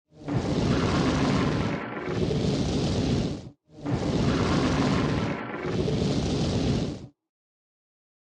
UI/UX
Gryphon Sleeping is a free ui/ux sound effect available for download in MP3 format.
yt_7bgXndPCaaQ_gryphon_sleeping.mp3